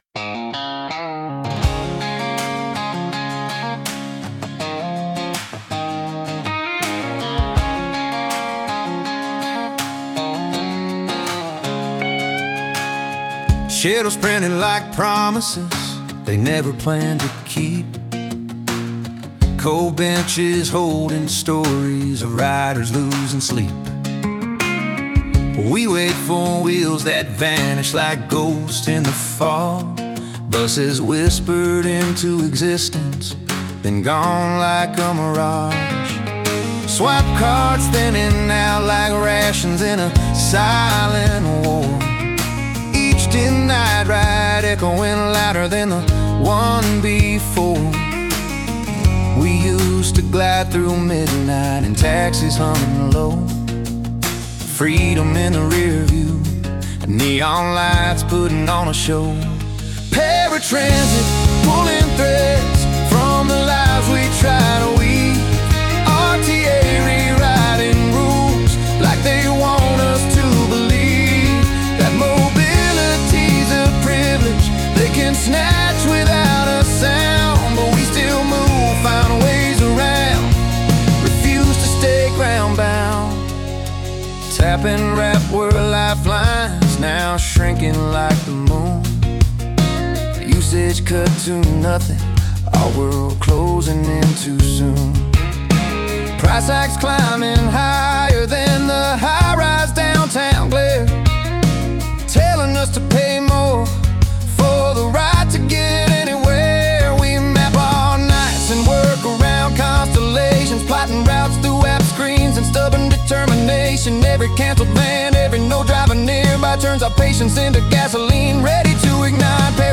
This is something I wrote about Paratransit. It's a song!